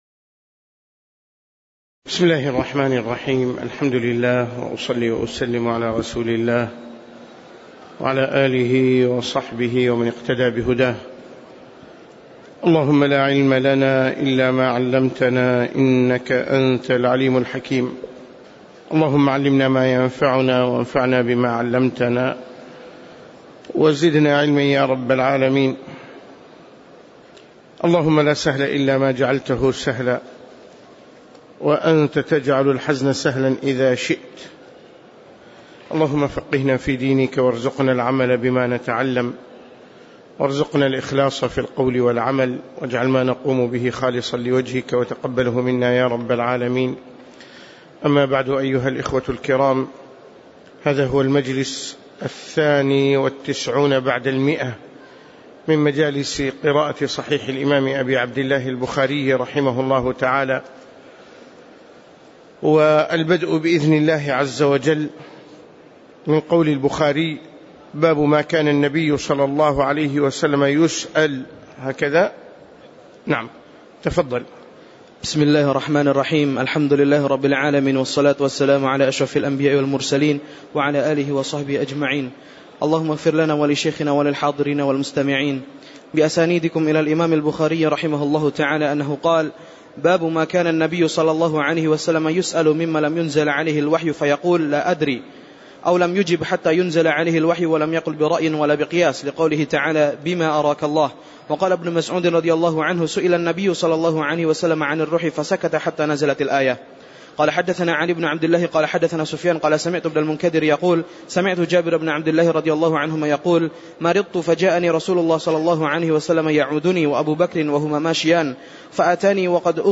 تاريخ النشر ٦ جمادى الأولى ١٤٣٩ هـ المكان: المسجد النبوي الشيخ